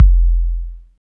25.09 KICK.wav